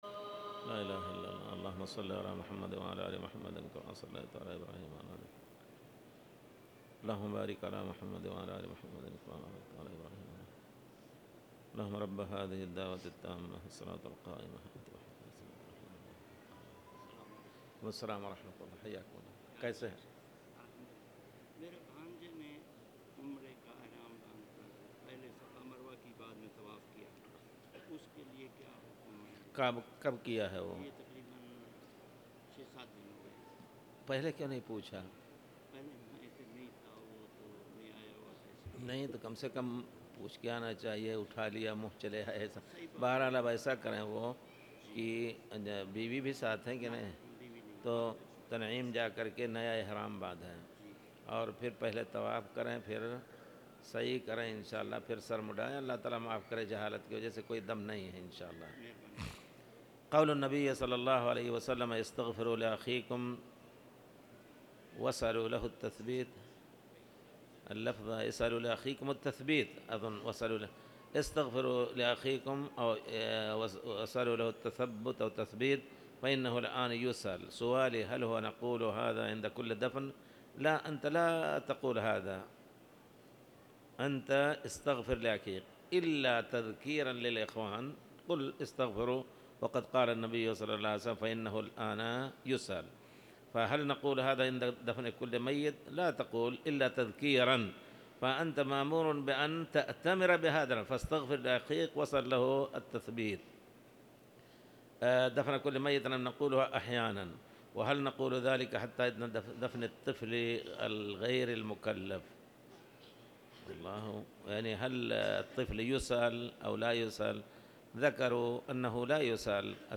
تاريخ النشر ٩ شوال ١٤٣٧ هـ المكان: المسجد الحرام الشيخ